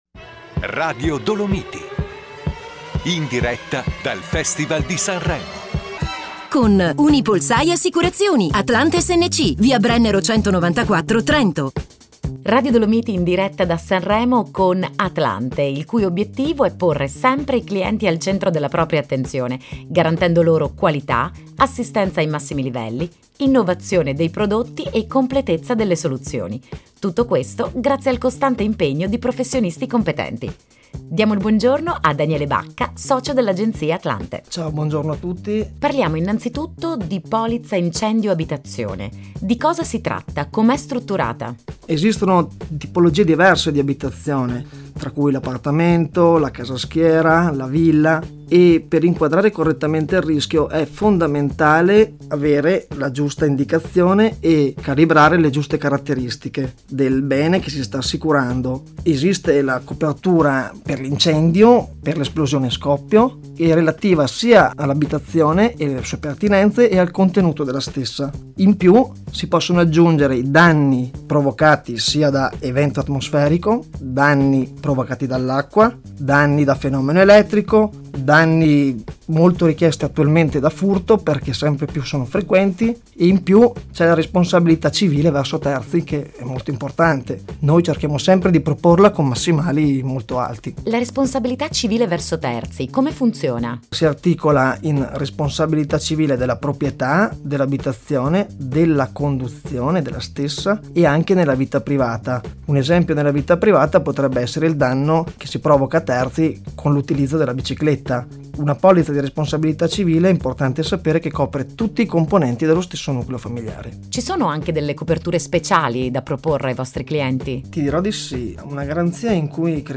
Ad inizio febbraio i nostri consulenti sono stati ospiti di Radio Dolomiti.